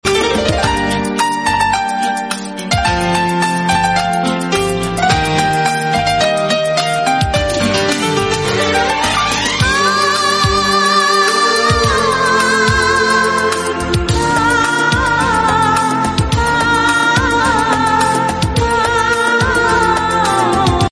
Albino red ayes lovebirds parrots